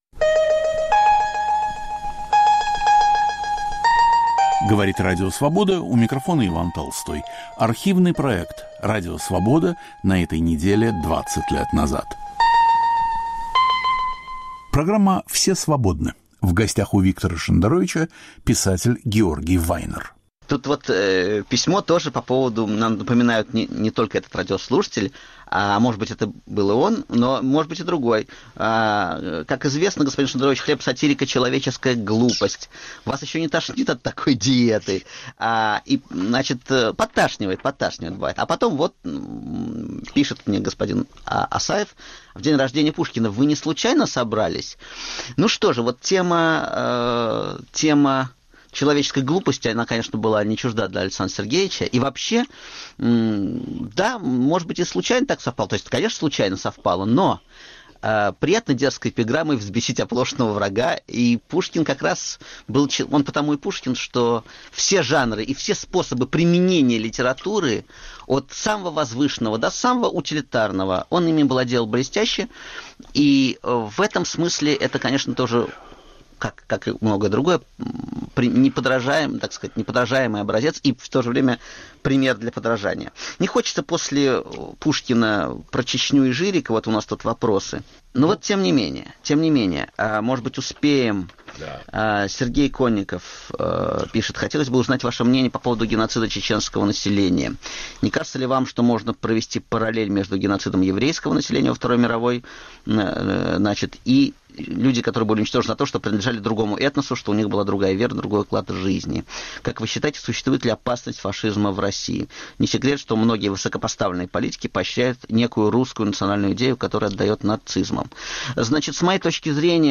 Один из братьев Вайнеров в Нью-Йоркской студии Радио Свобода.